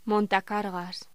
Locución: Montacargas
voz